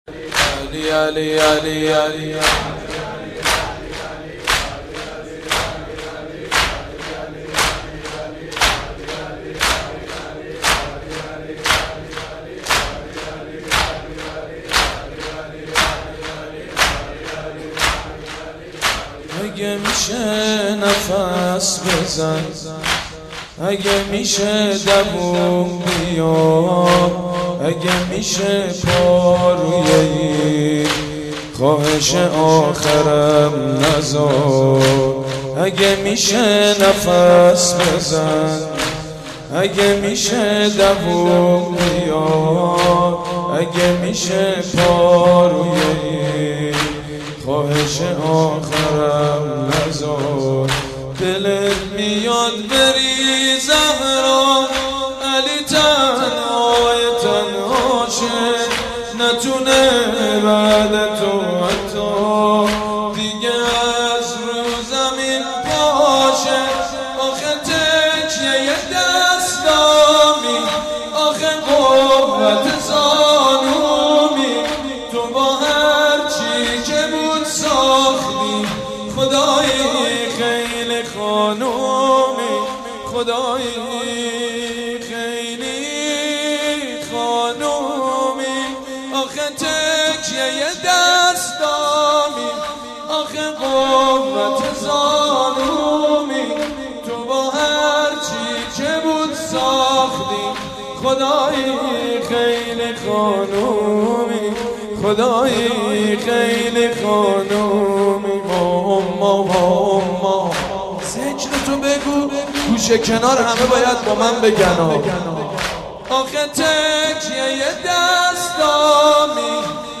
سینه زنی، شهادت حضرت فاطمه زهرا(س)